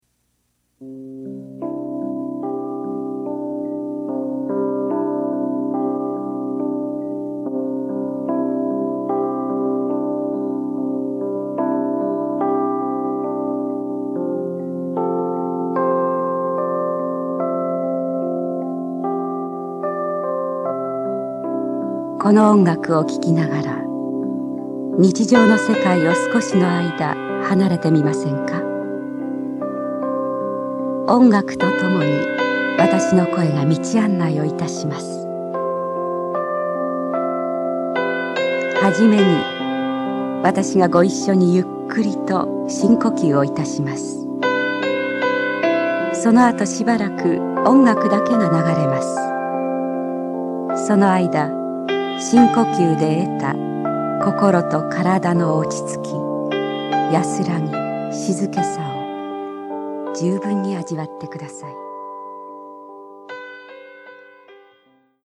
mp3はラジカセ(東芝 TY-CDX92)で作成しました。
mp3音源サンプル(A面冒頭)
A面：話と音楽 22分